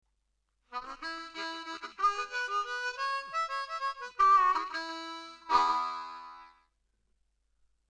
For now though, we’re using a regular Richter tuned 10 hole diatonic harmonica.
We’re in cross harp (second position) on an A harmonica – key of E major.
Now, using the mid octave, let’s start to throw things around a bit and see what comes out..
Country-Lick-1.mp3